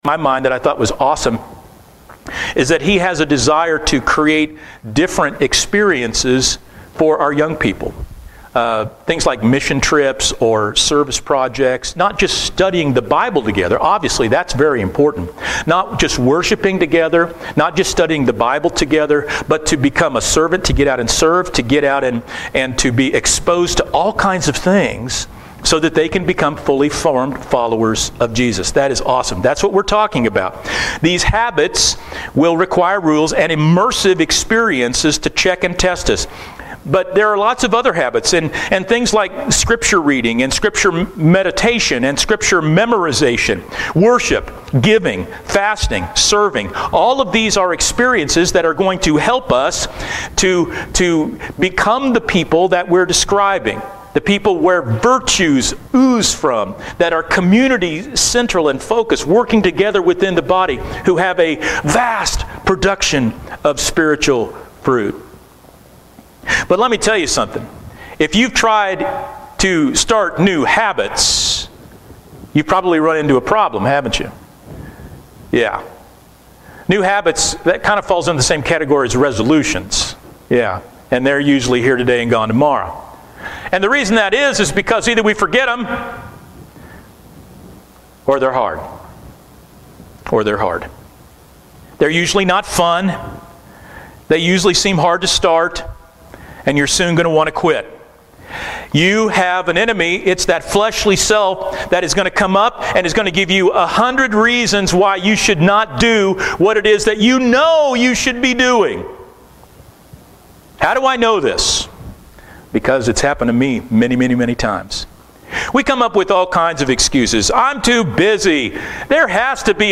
Sermon only